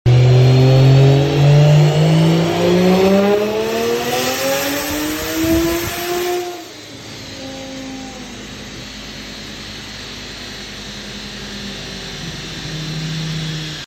Listen To That Big Turbo! sound effects free download
Mp3 Sound Effect Listen To That Big Turbo! 600hp N55. 10.8s @ 213km/h full trim.